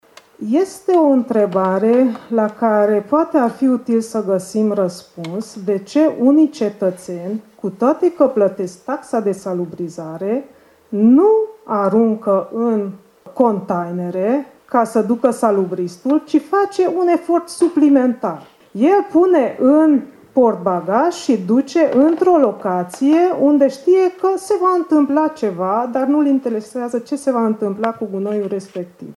În acest sens, Radio Tg.Mureş a iniţiat şi organizat miercuri, 15 noiembrie, o dezbatere la care au participat autoritățile publice locale și județen,  dar și reprezentanţi ai ONG-urilor de profil.